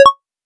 Normal_Mode « Resources - auditory.vim - vim interface sounds